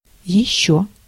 Ääntäminen
RP : IPA : /jɛt/ US : IPA : [jɛt]